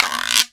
094 - Guiro.wav